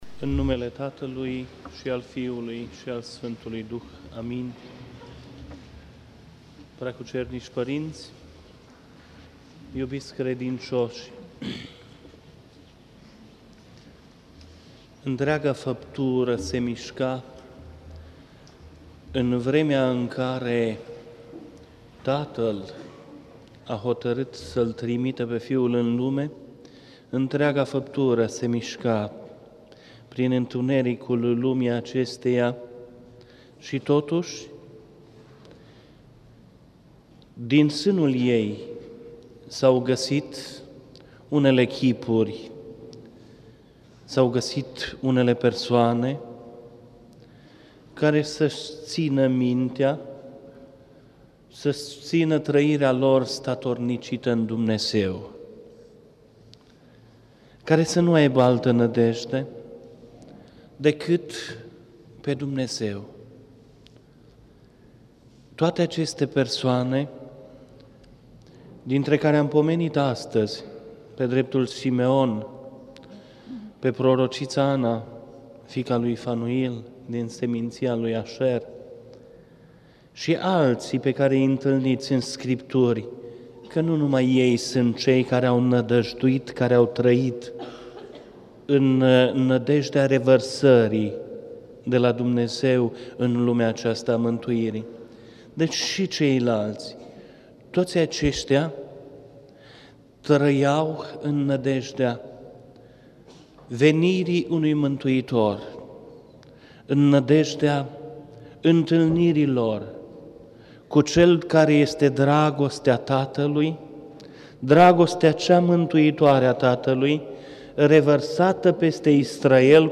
Predică la sărbătoarea Întâmpinării Domnului
Cuvânt de învățătură
rostit la sărbătoarea Întâmpinării Domnului, în anul 2003, la Catedrala Mitropolitană din Cluj-Napoca.